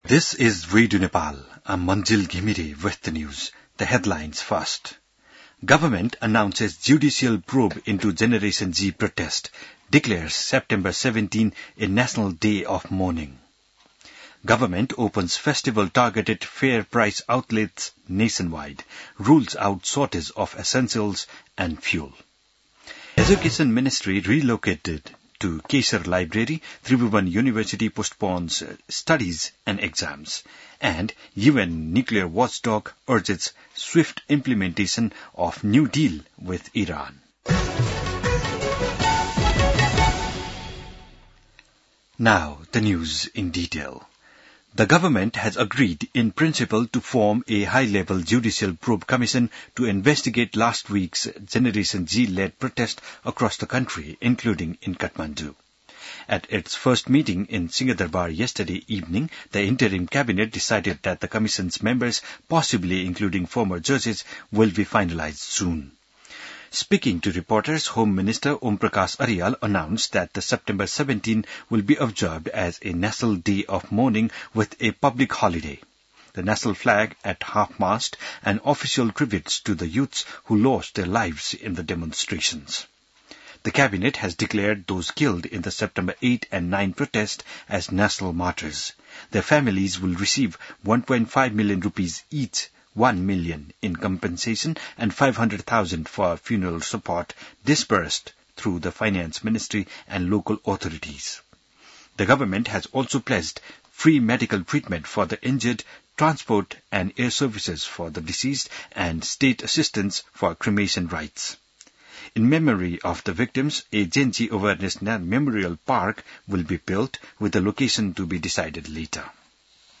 बिहान ८ बजेको अङ्ग्रेजी समाचार : ३१ भदौ , २०८२